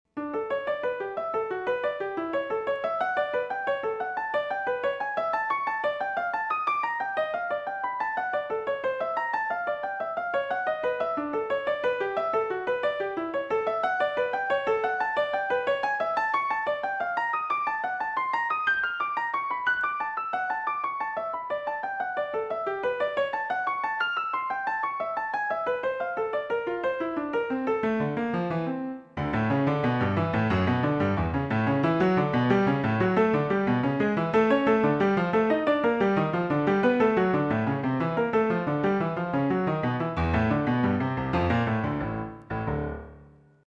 Negligible piano piece, hot off the nothing!
So here’s a new quantized version.
Plus the hammer noise on that piano sound was bothering me, so this time let’s hear my other, equally fake piano.